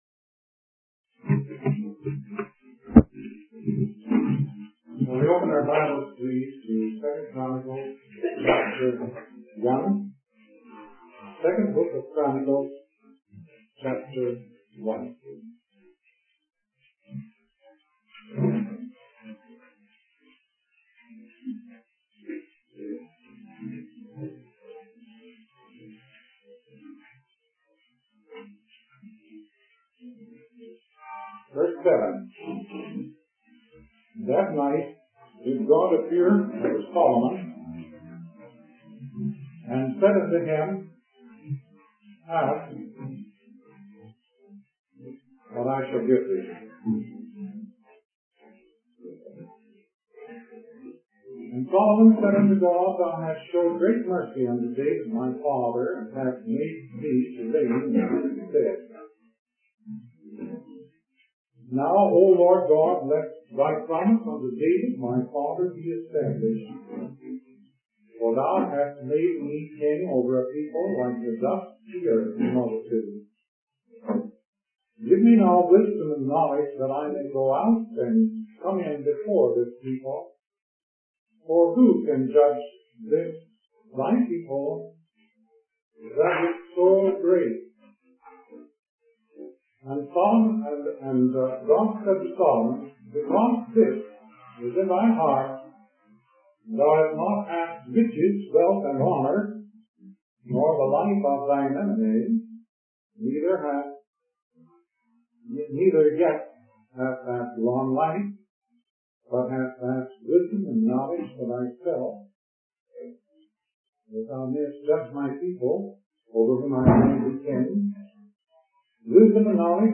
In this sermon, the preacher focuses on the story of Solomon in the book of 2 Chronicles.